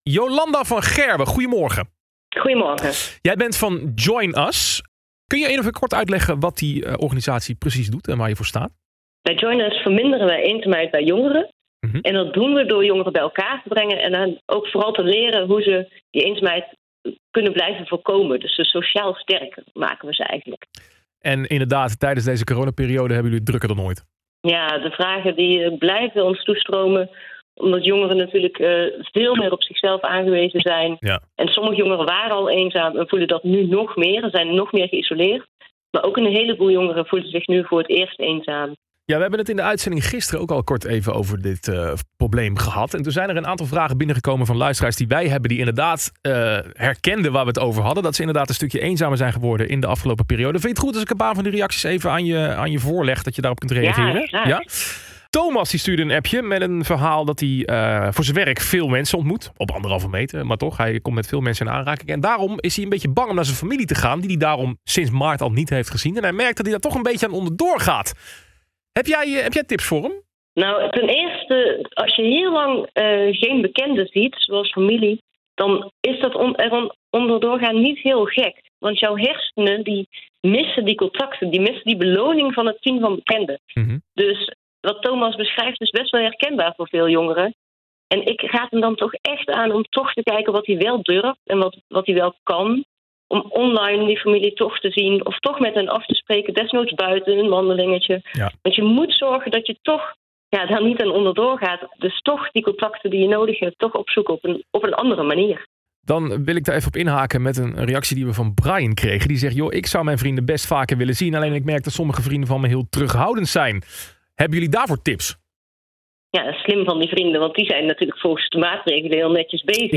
In de ochtendshow van 6 januari 2021 op SLAM! FM werd het onderwerp eenzaamheid onder jongeren besproken. Naar aanleiding van een oproep onder luisteraars, meldden jongeren zich met hun verhaal.